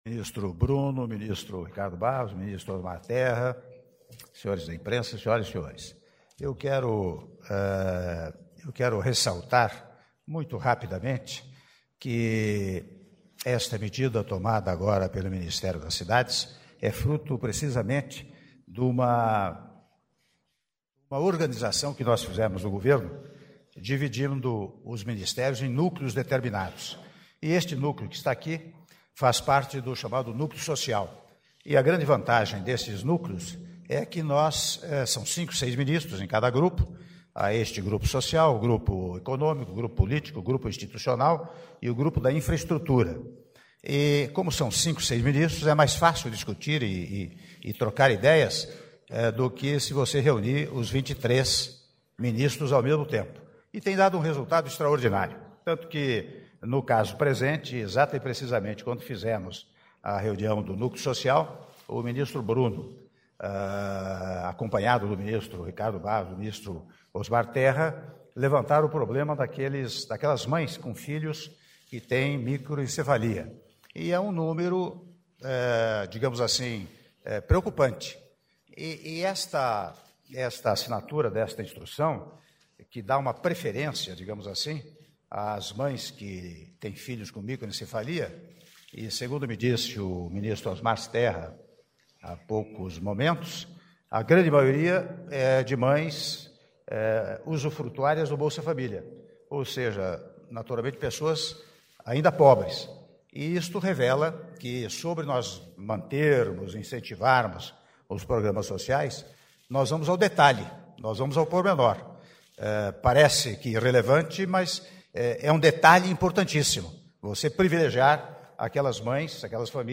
Áudio do discurso do presidente da República em exercício, Michel Temer, durante cerimônia de anúncio de nova norma do Programa Minha Casa Minha Vida (04min35s) - Brasília/DF